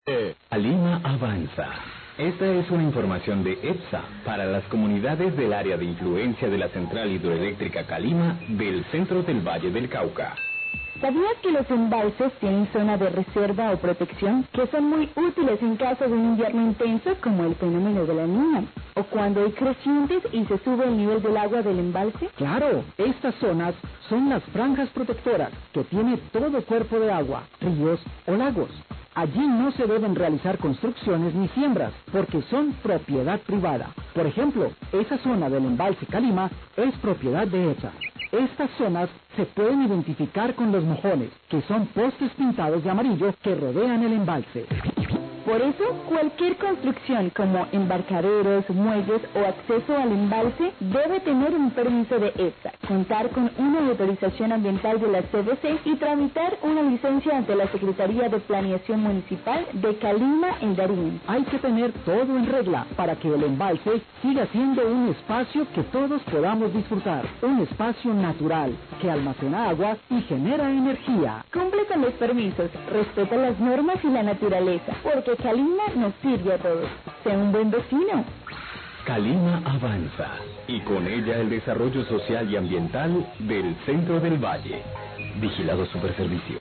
CAPSULA INFORMATIVA DE EPSA - CALIMA AVANZA, 7-25AM
Radio